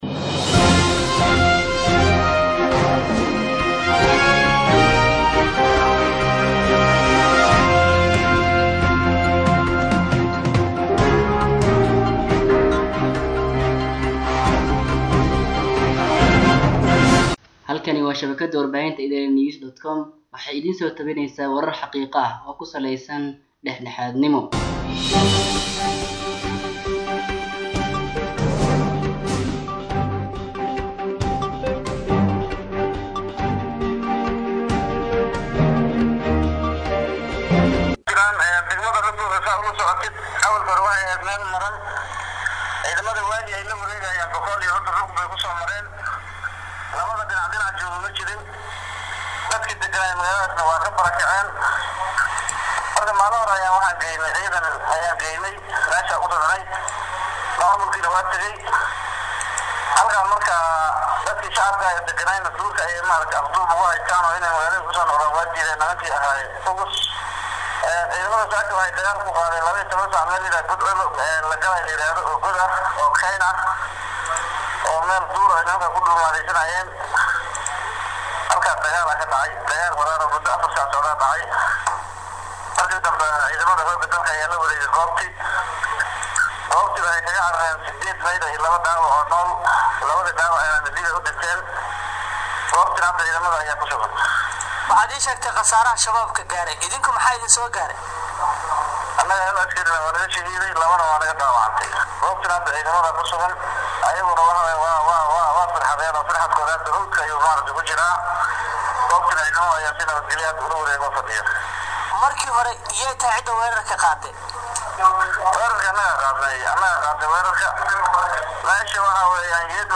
Dhageyso Wareysi:Taliska Ciidanka Oo Ka Warbixiyey Dagaal Al-shabaab Iyo Ciidanka Dowladda Ku Dhexmaray Gobolka Bakool